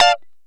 Lng Gtr Chik Min 10-D3.wav